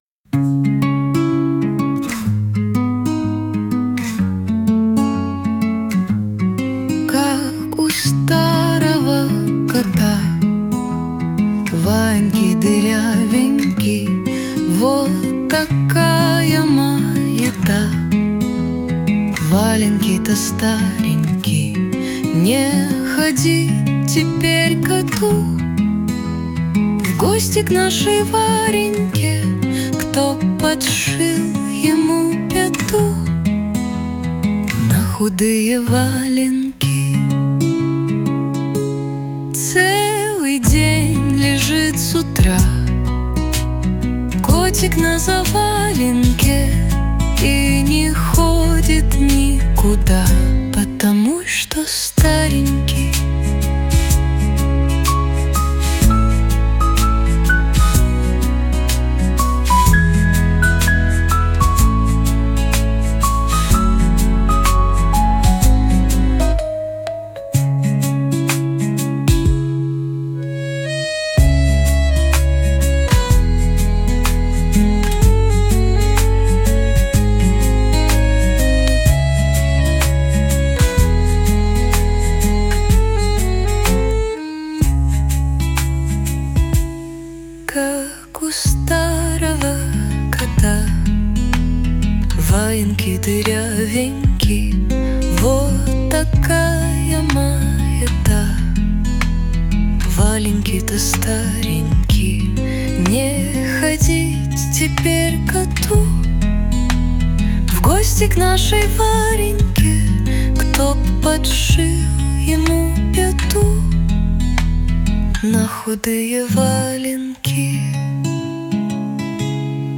• Аранжировка: Ai
• Жанр: Детская